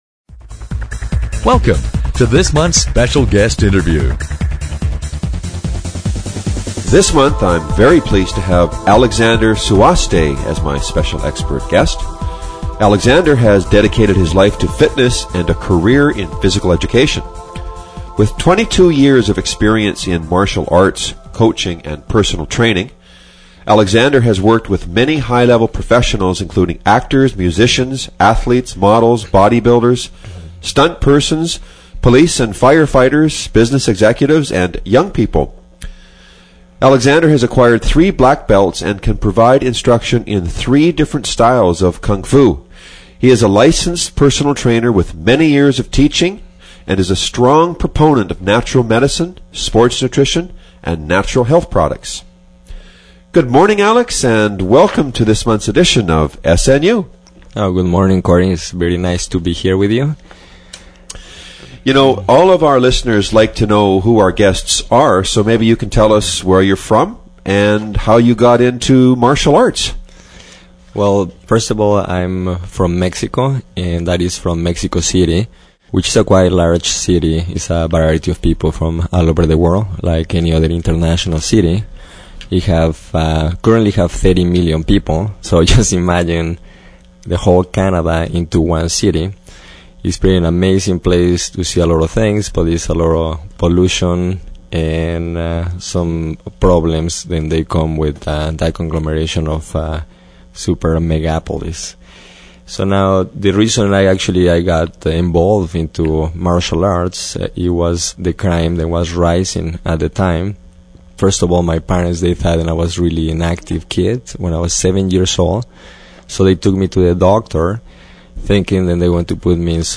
Special Guest Interview Volume 4 Number 11 V4N11c